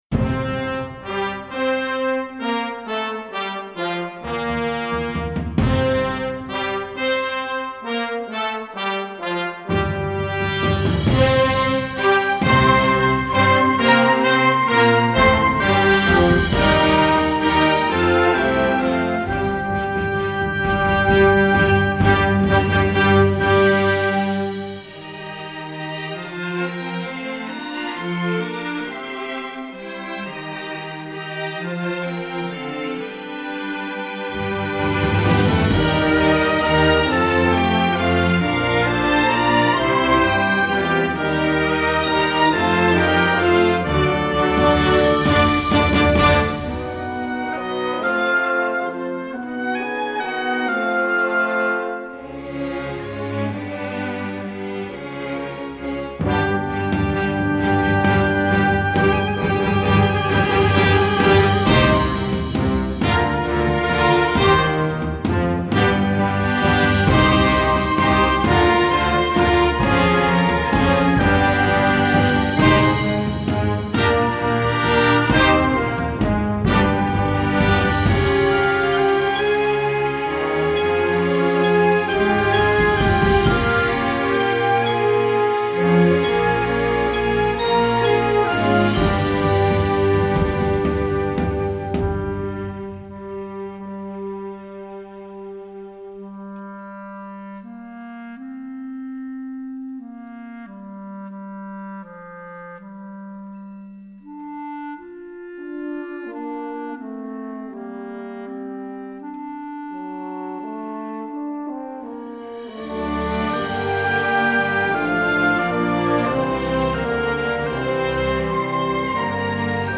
Instrumentation: full orchestra
instructional, children